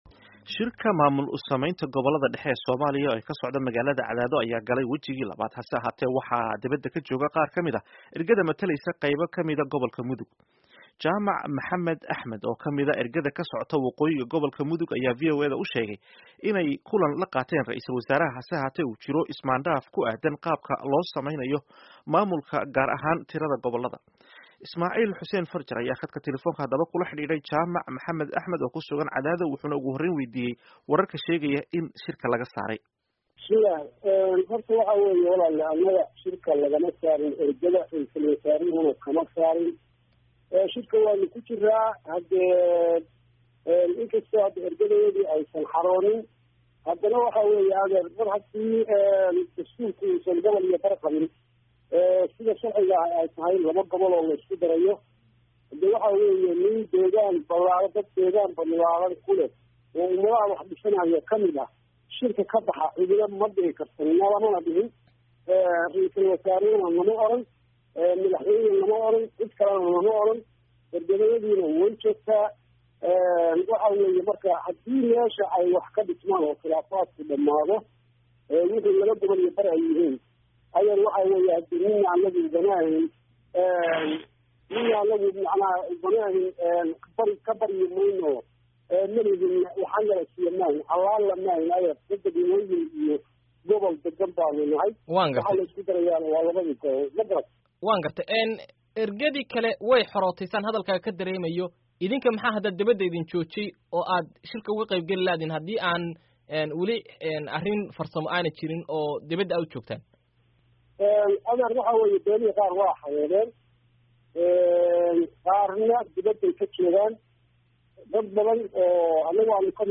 Waraysiga Shirka Cadaado